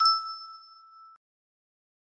Empty music box melody